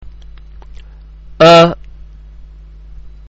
ഉച്ചാരണം (പ്ലേ ബട്ടണ്‍ അമര്‍ത്തുക)
001_Alif[1].mp3